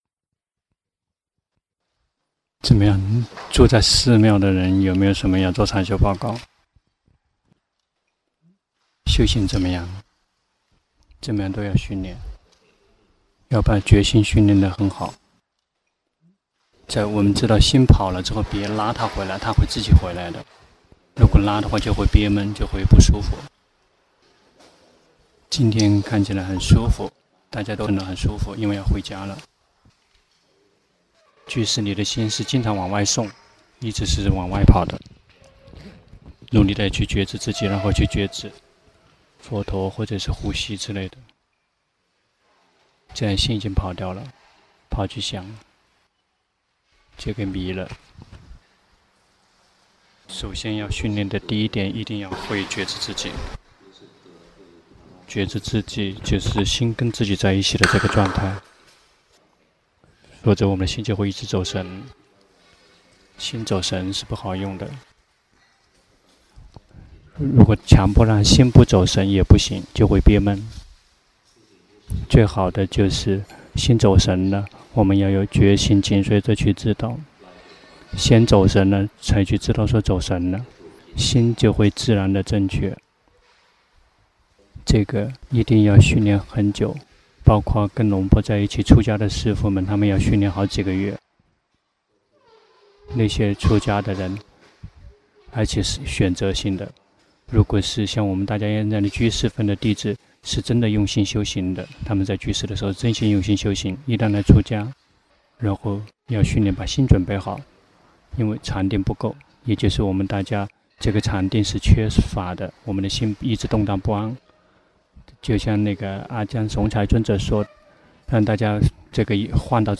長篇法談｜覺性使人美￼